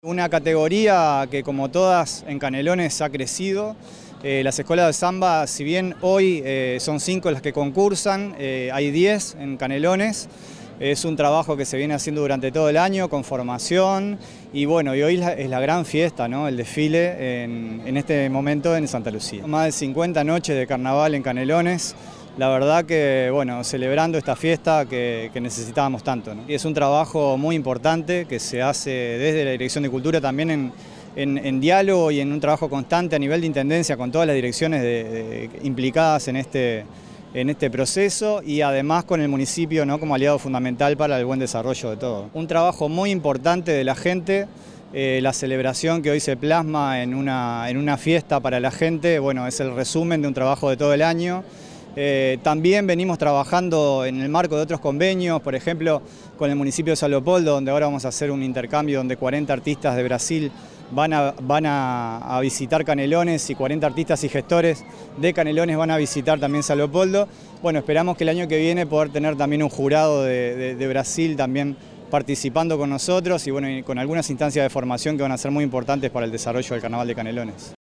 El Director General de Cultura del Gobierno de Canelones, Sergio Machín, dijo que la noche del desfile es el resultado de todo un año de trabajo, realizado en conjunto entre la Dirección de Cultura, en diálogo constante con todas las direcciones implicadas en este proceso, y con el Municipio de Santa Lucía como un aliado fundamental para el buen desarrollo de la actividad.